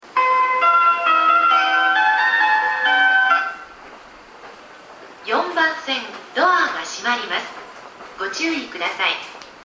発車メロディー途中切りです。